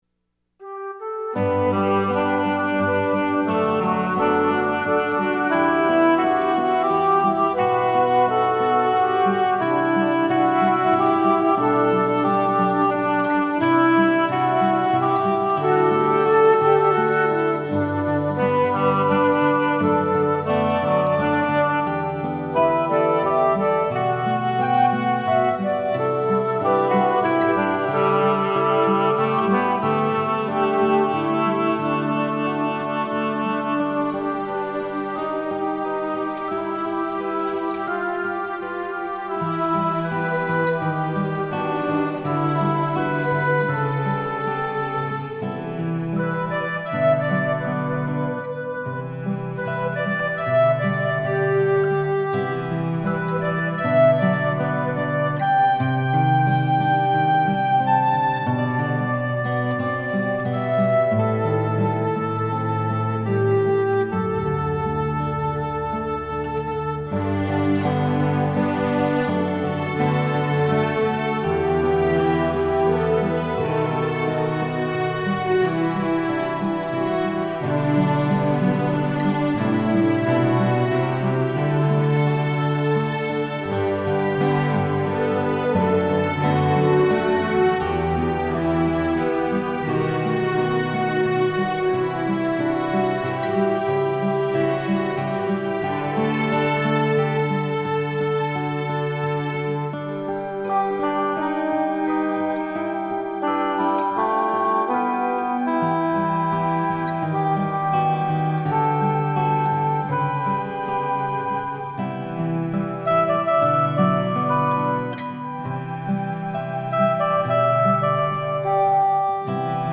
instrumental song